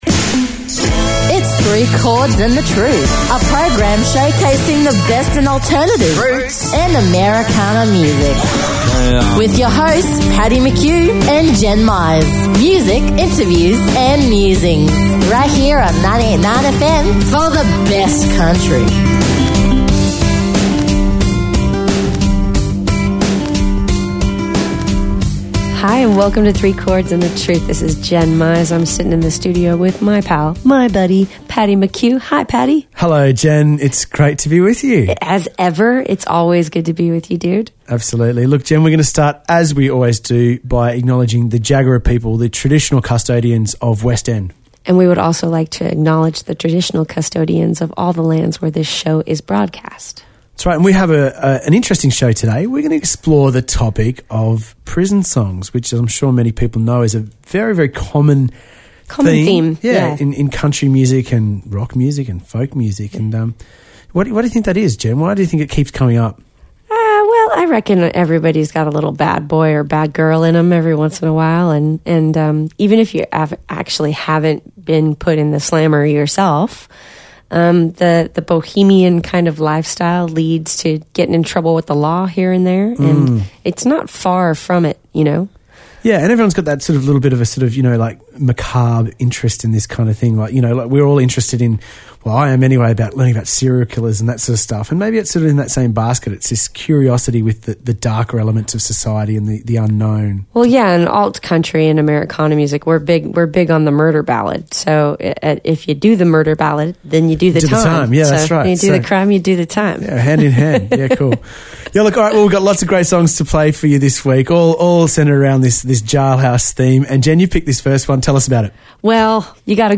Music, interviews and musings every Thursday nights from 6pm and the repeat from 10am Sunday morning.